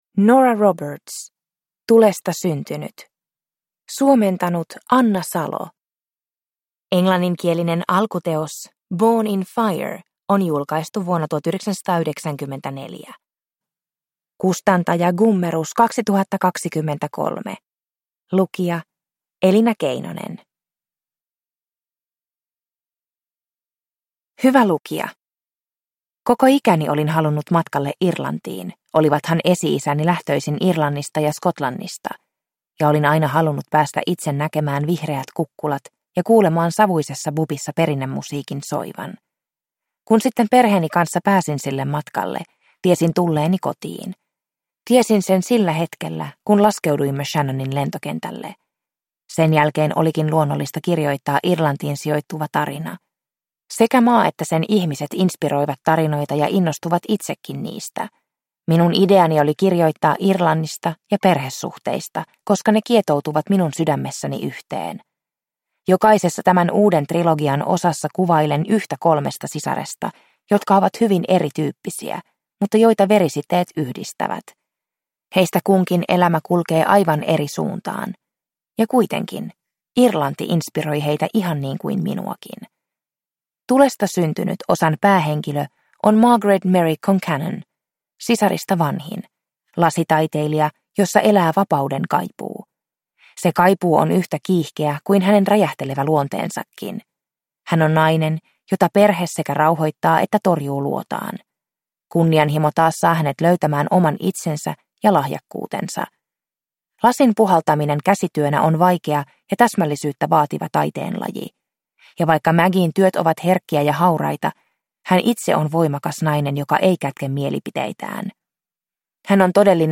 Tulesta syntynyt – Ljudbok – Laddas ner